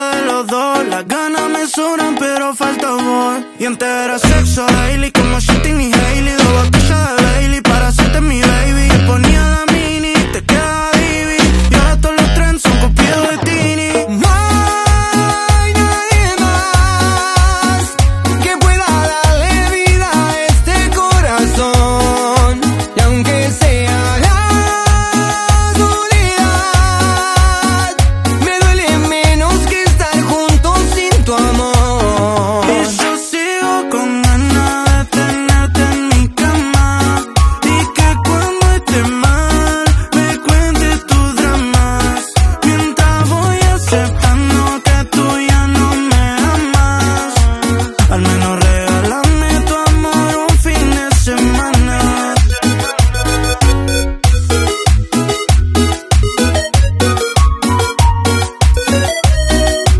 Ehhh sound effects free download